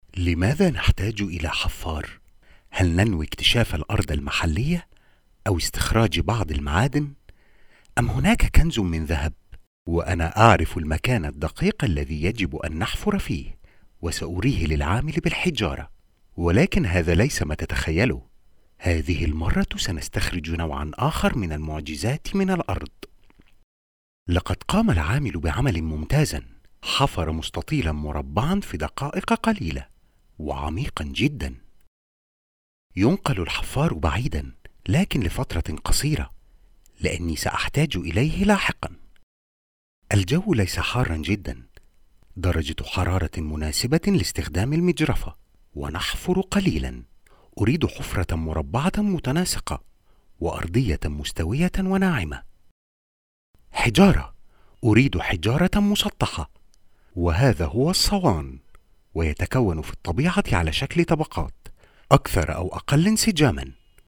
Male
Adult (30-50)
Narration